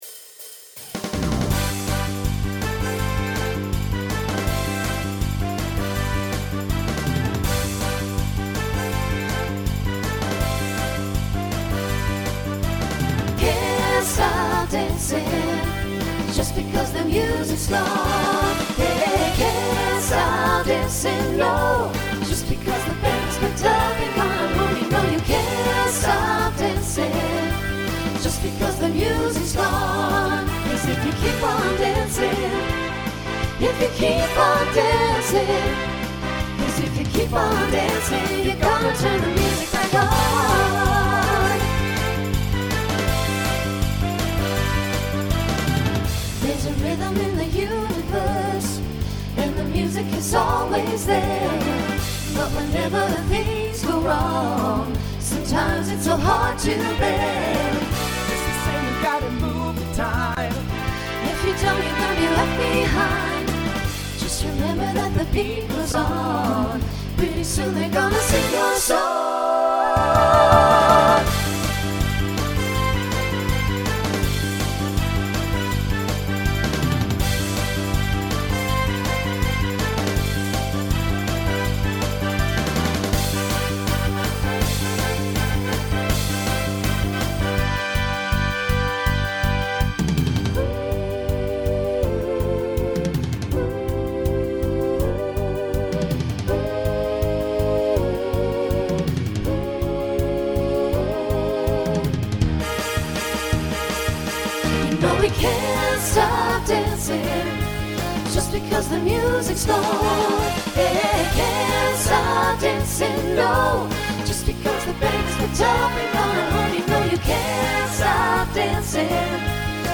Decade 1970s Genre Pop/Dance Instrumental combo
Voicing SATB